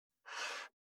389,机の上をスライドさせる,スー,ツー,
効果音